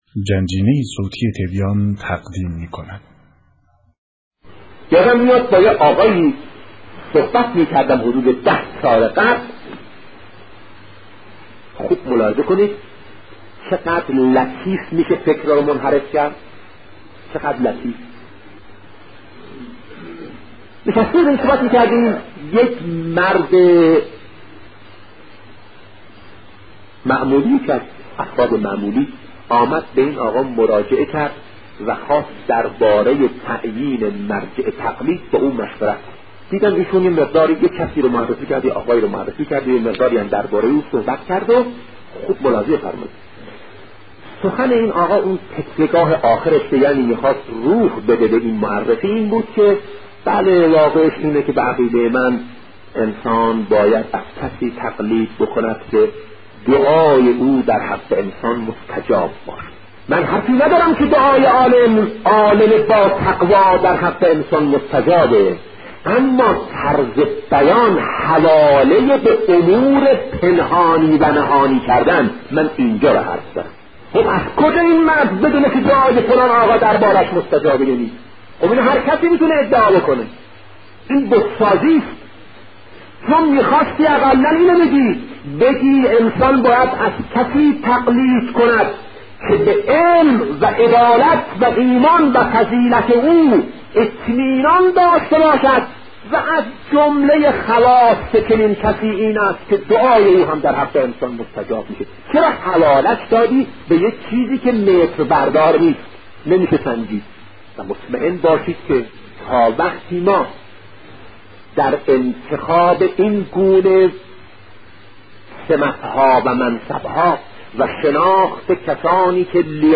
بیانات بزرگان